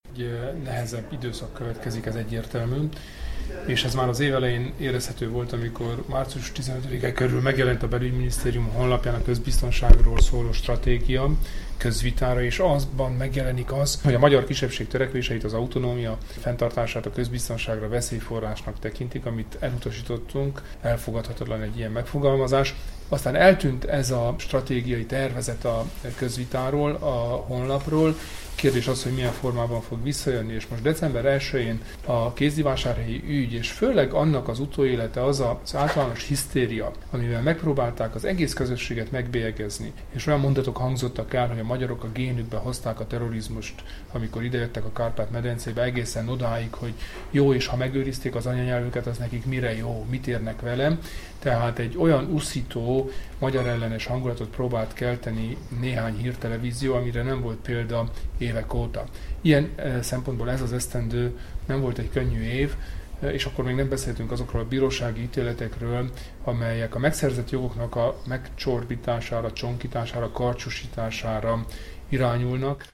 A kisebbségi jogok érvényesítése terén 2015 a visszalépés évének tekinthető Romániában, amely ebben a vonatkozásban továbbra is következmények nélküli országnak bizonyult – hangoztatta Kelemen Hunor, a Romániai Magyar Demokrata Szövetség (RMDSZ) elnöke csütörtöki évértékelő sajtóértekezletén Kolozsváron.
Hallgassa meg Kelemen Hunor nyilatkozatát!
kelemen-hunor-evertekelo-sajtotajekoztatoja-kolozsvaron.mp3